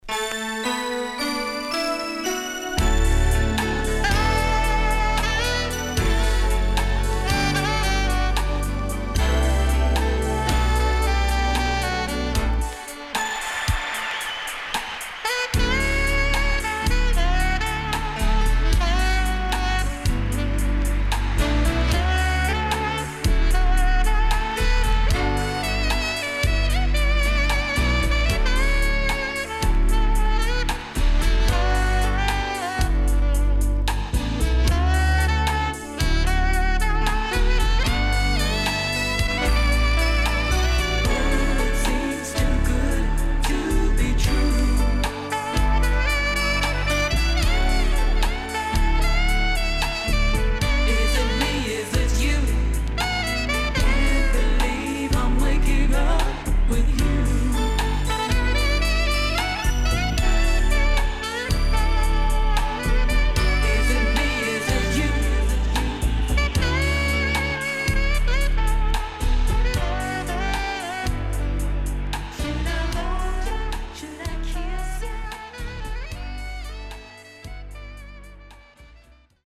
HOME > DANCEHALL
SIDE A:少しチリノイズ、プチノイズ入ります。